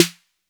808-Snare23.wav